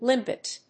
音節lim・pet 発音記号・読み方
/límpɪt(米国英語), ˈlɪmp.ɪt(英国英語)/